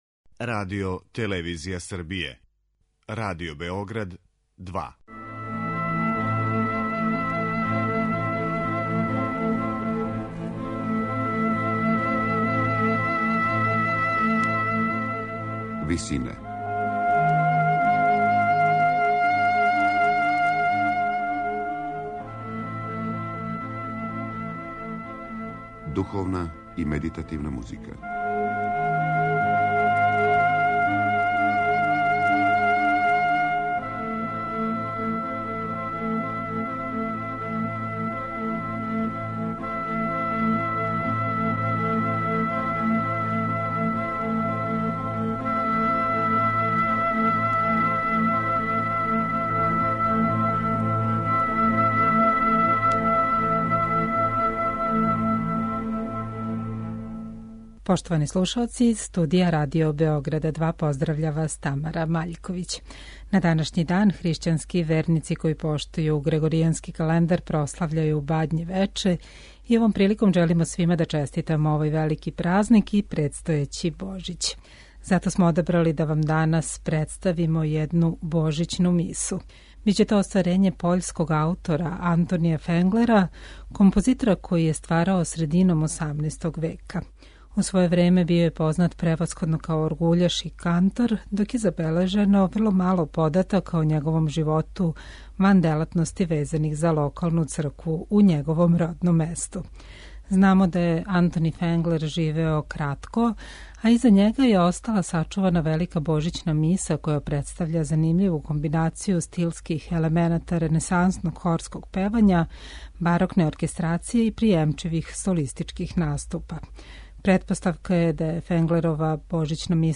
пољског барокног ансамбла